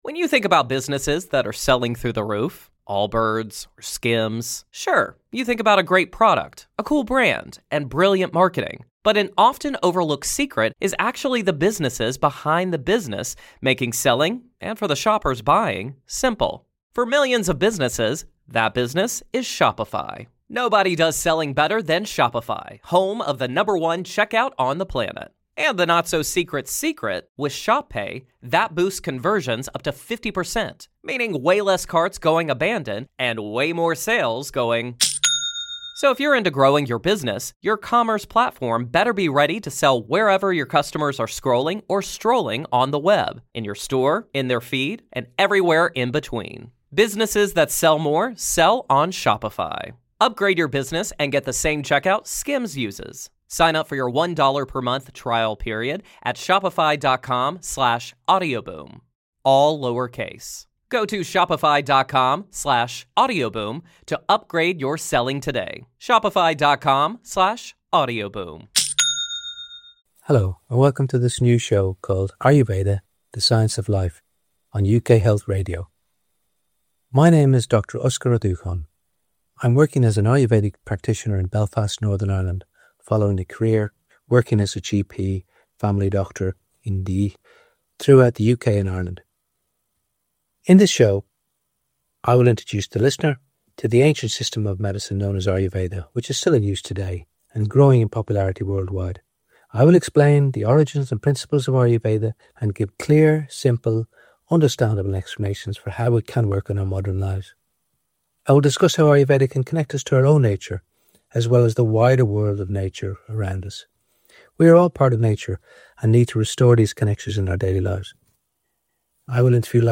I'll interview therapists and health experts who share a holistic view of wellness.